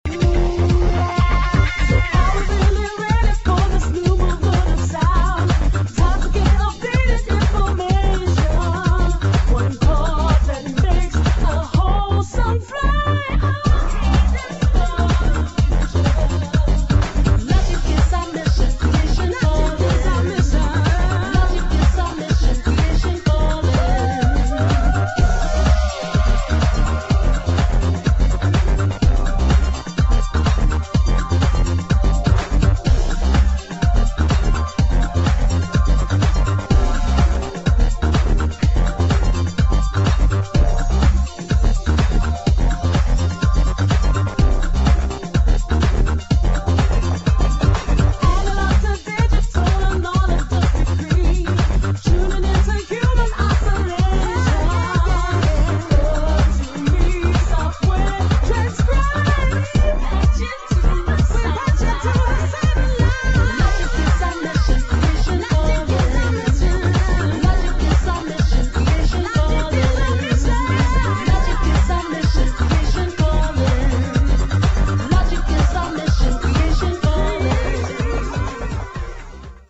[ JAZZ / FUNK / SOUL / HOUSE ]